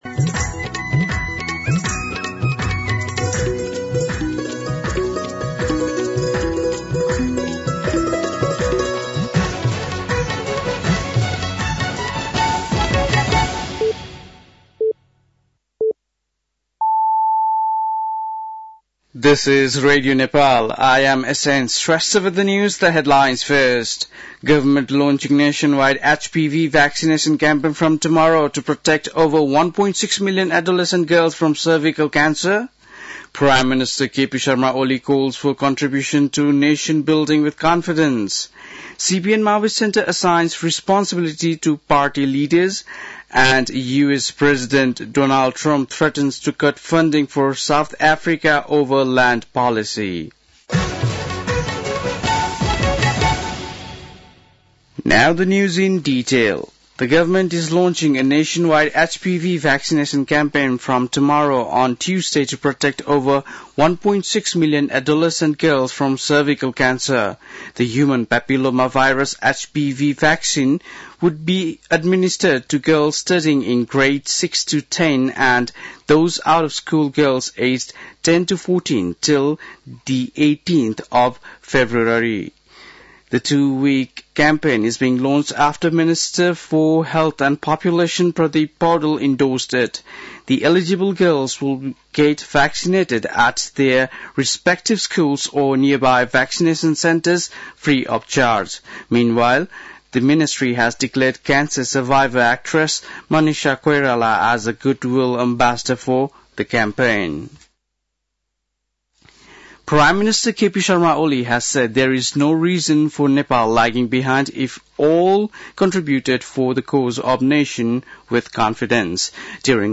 बेलुकी ८ बजेको अङ्ग्रेजी समाचार : २२ माघ , २०८१
8-PM-English-News-10-21.mp3